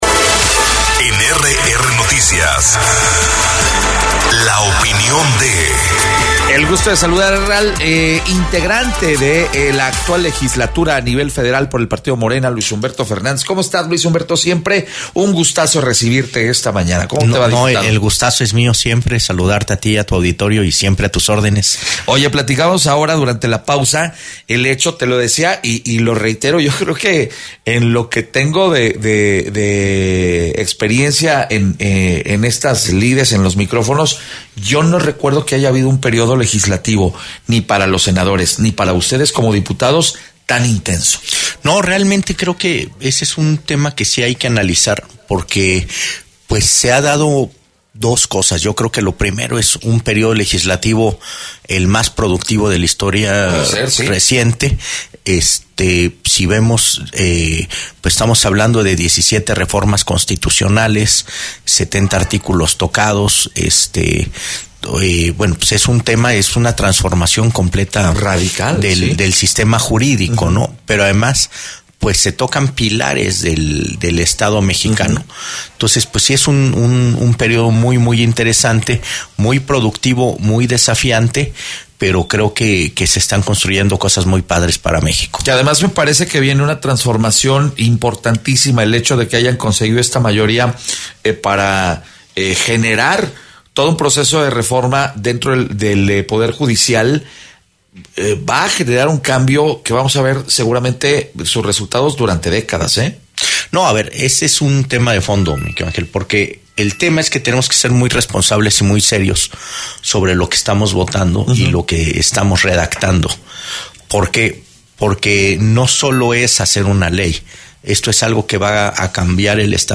EntrevistasPodcastSin categoría